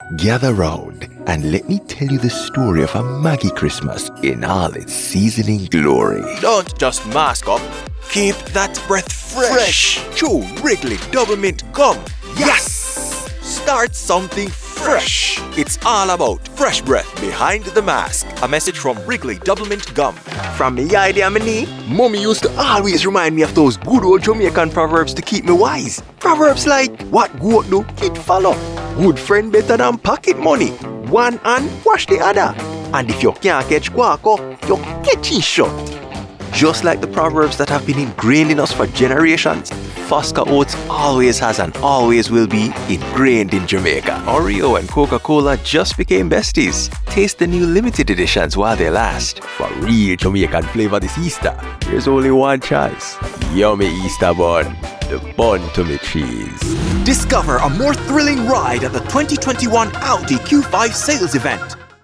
Anglais (Caraïbes)
Anglais (jamaïcain)
Démo commerciale
Il travaille à temps plein depuis son studio professionnel à domicile bien équipé.
- Des voix off de qualité HD en permanence !
- Microphone canon hypercardioïde Synco D2
- Interface audio Solid State Logic 2+
BarytonBasseContre-ténorProfondBas